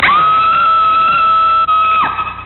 PERSON-Scream+5
Tags: combat